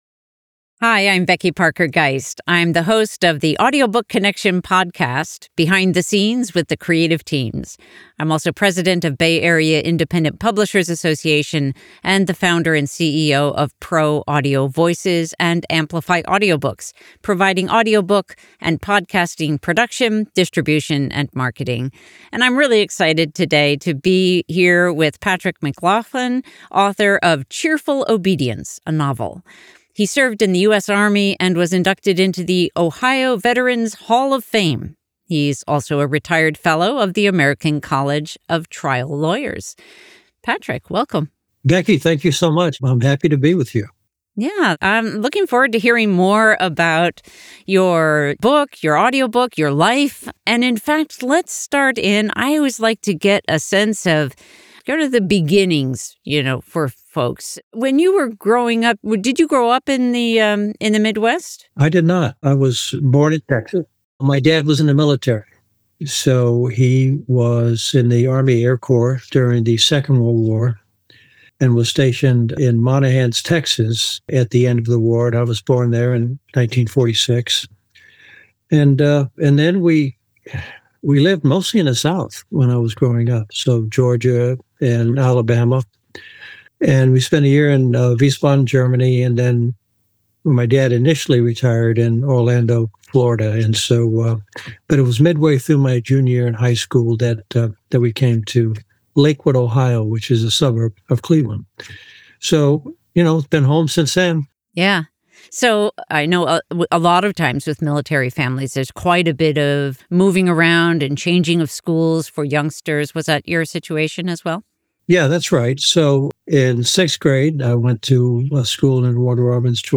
Author’s interview by Pro Audio Voices.
Patrick-McLaughlin-interview-Web-Version.mp3